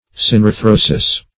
Search Result for " synarthrosis" : The Collaborative International Dictionary of English v.0.48: Synarthrosis \Syn`ar*thro"sis\, n.; pl.